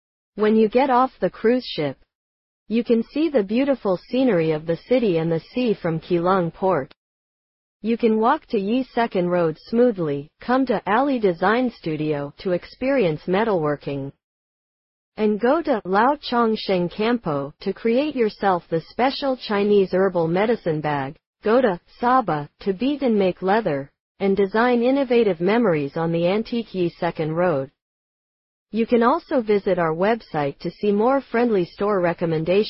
Essai gratuit d'une minute de la visite audio de cet itinéraire